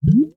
lavapop.ogg